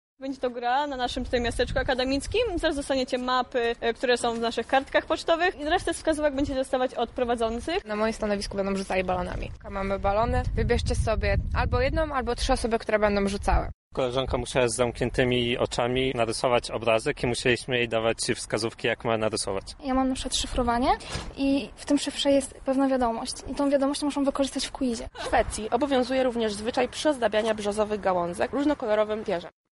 Na miejscu był nasz reporter: